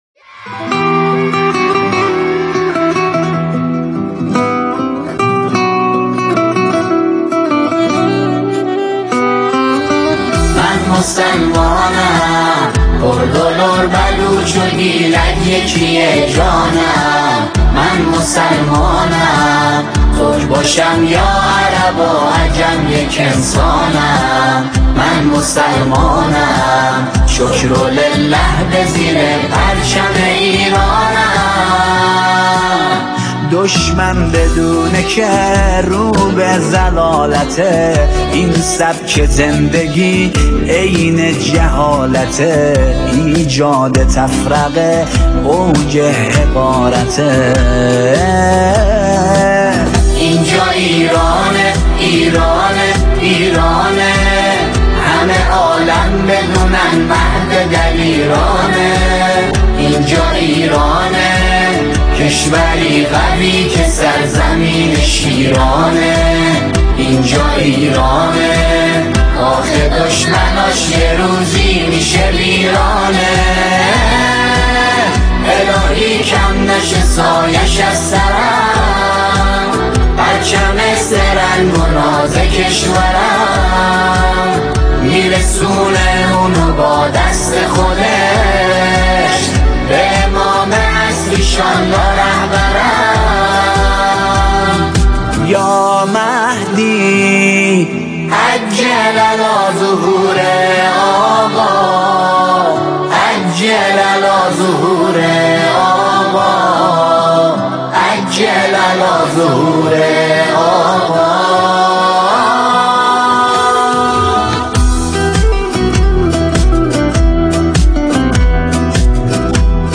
ژانر: سرود ، سرود انقلابی ، سرود مناسبتی